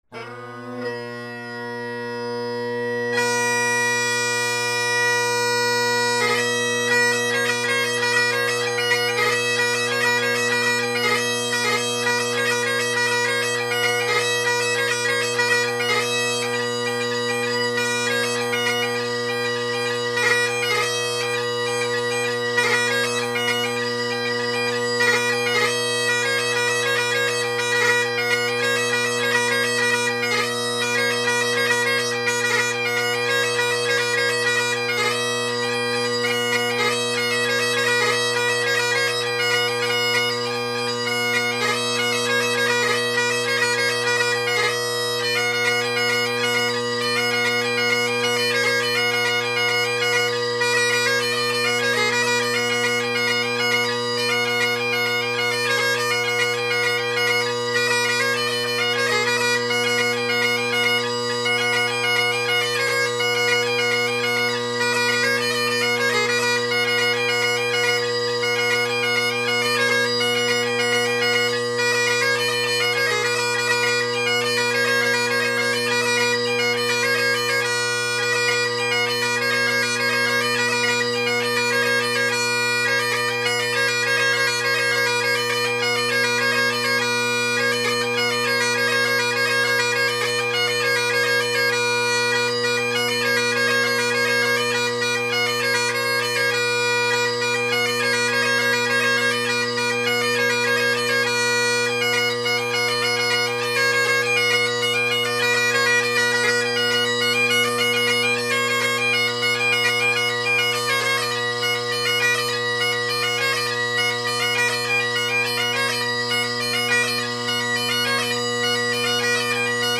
Drone Sounds of the GHB, Great Highland Bagpipe Solo
The recordings below were artificially amplified as I forgot to reset the gain on my Zoom H2 after setting it to a quieter setting yesterday.
Still a little tenor quiet, but I believe this lends just a little more ease when tuning the drones along with some added stability.